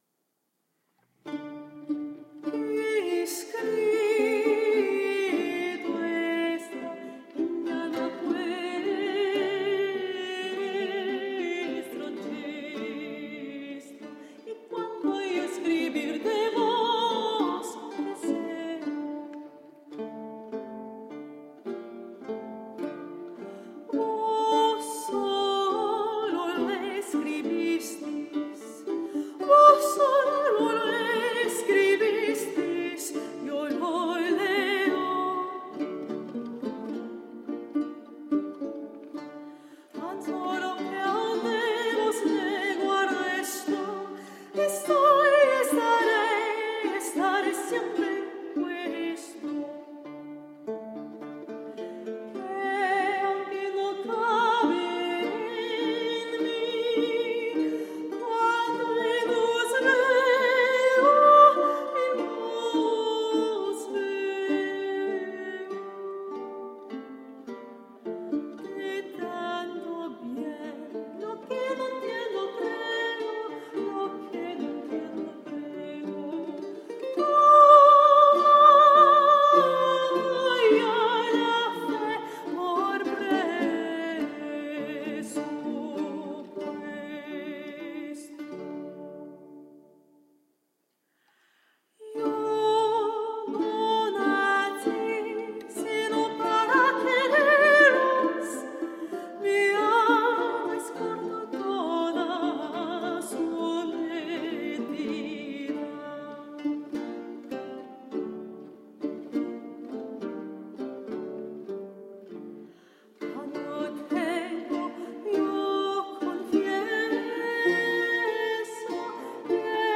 Dance music and songs of renaissance spain.